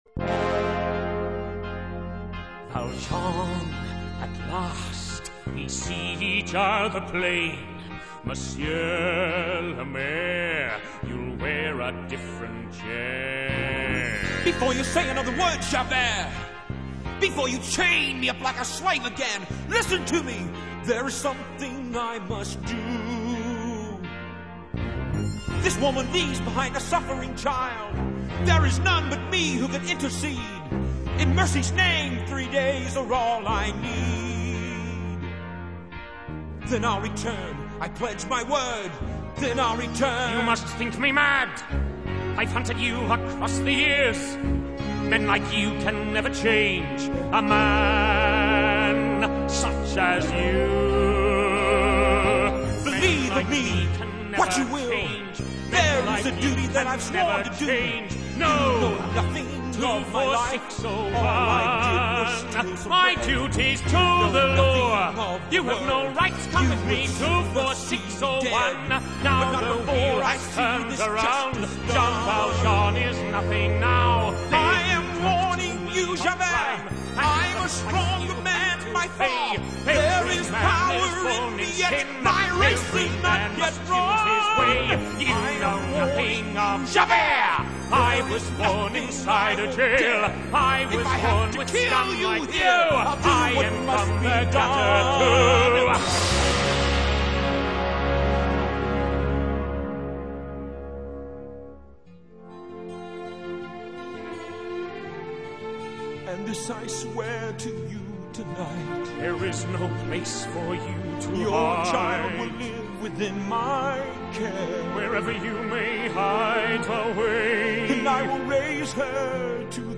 國際版裡換個人唱後，卻表現地很陽剛，一付若 Javert 不放他走就要他好看。